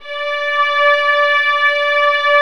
VIOLINS EN-L.wav